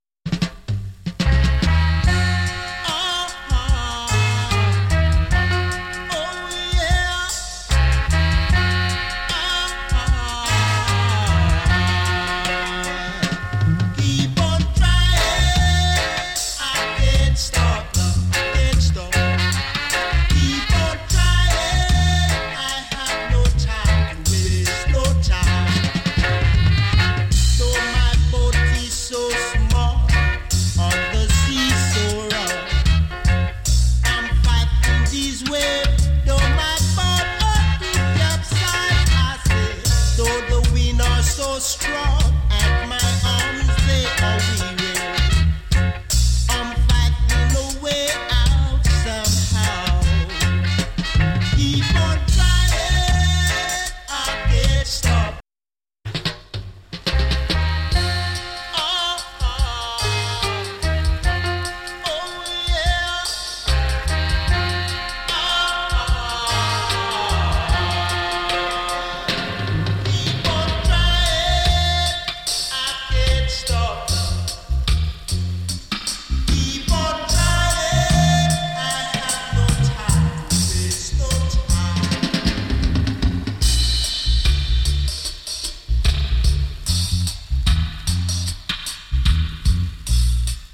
FINE ROOTS ROCK